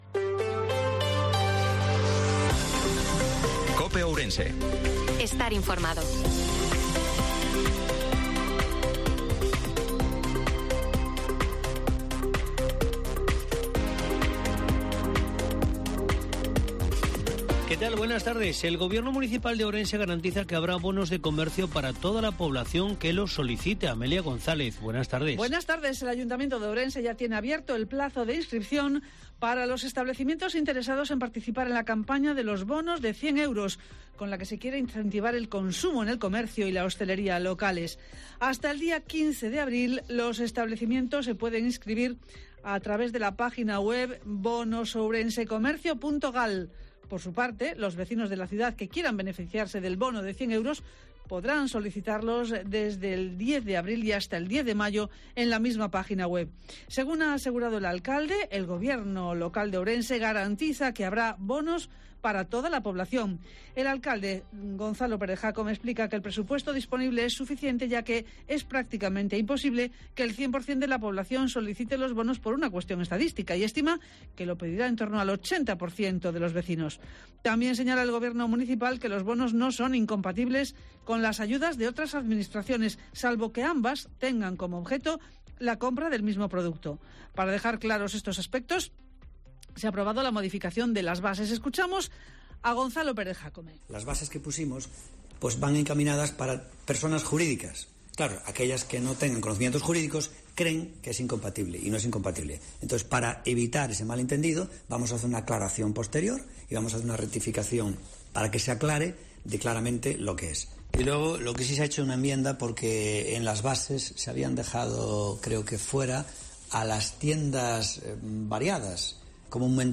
INFORMATIVO MEDIODIA COPE OURENSE-27/03/2023